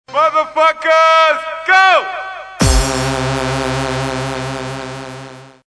Kermis Jingle's  2013
Jingle-10-Motherf go-.mp3